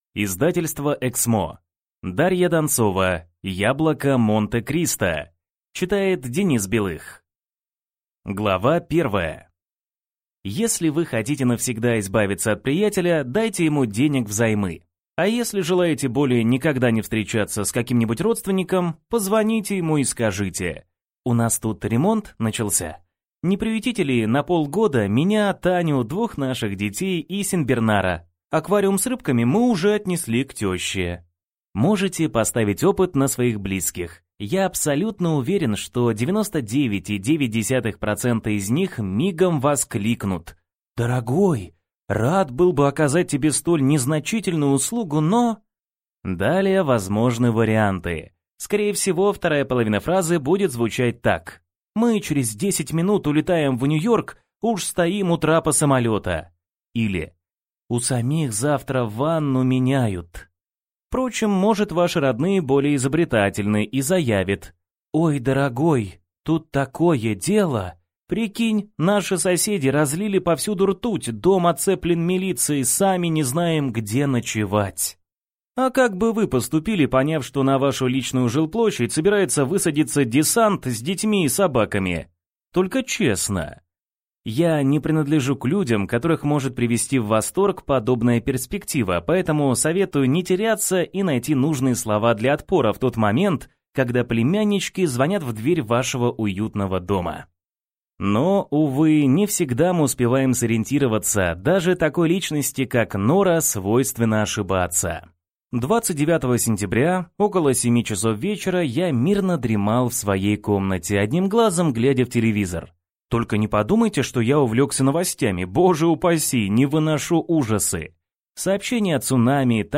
Аудиокнига Яблоко Монте-Кристо | Библиотека аудиокниг